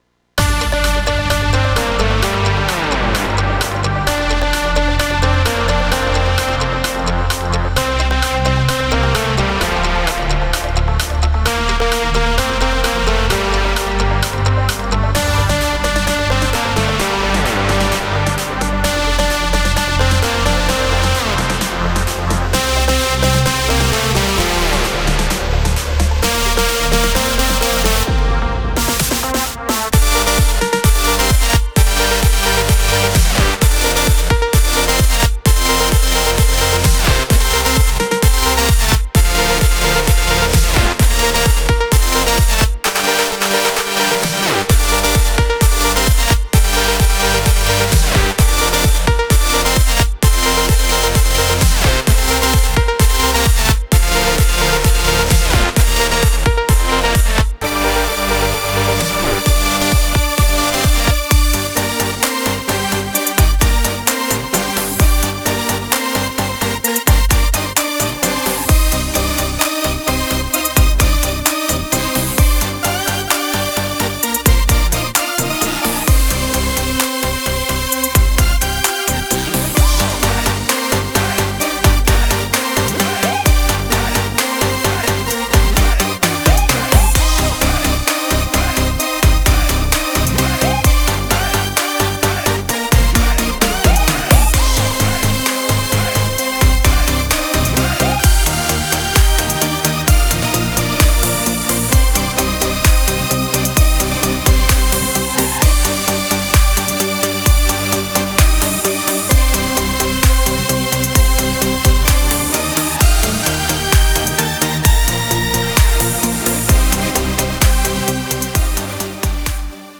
מקצבים שבניתי בטיירוס
מקצבים שבניתי אתמול והיום 001 YRTE.wav